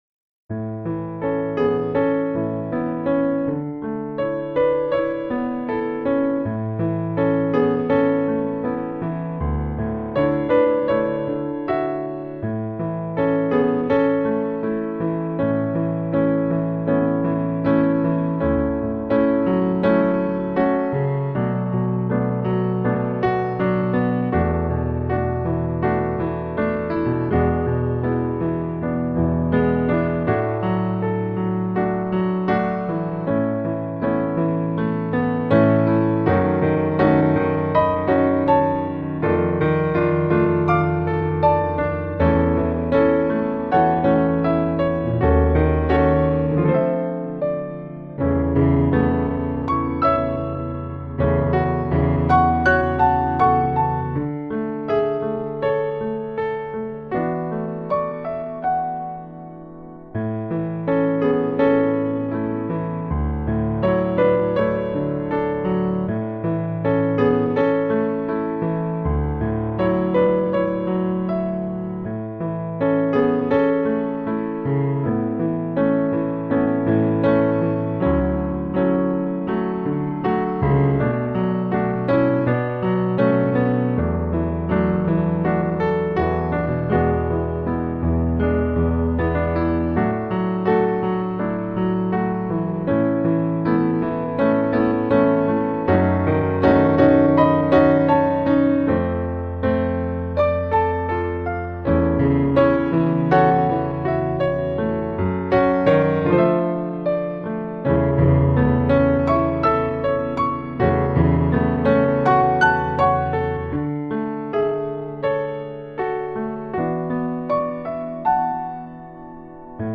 In This Very Room (piano only).mp3